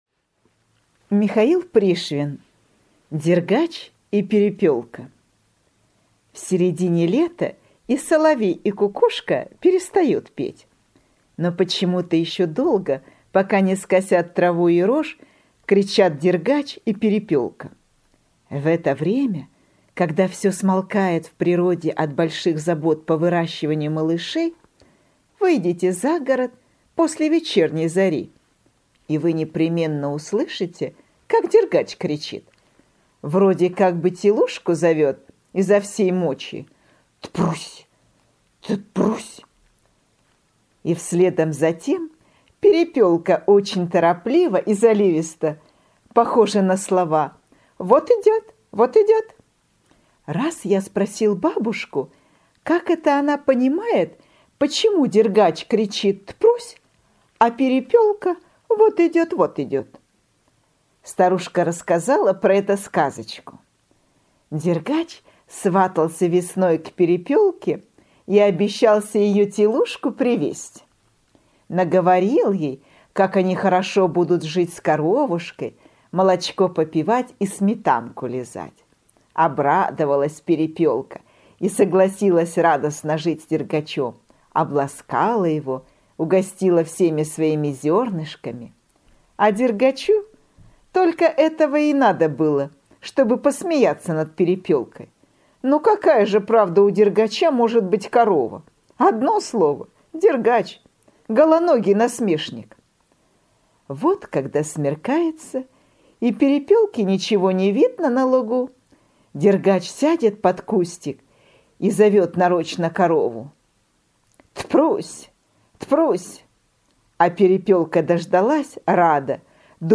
Дергач и перепелка – Пришвин М.М. (аудиоверсия)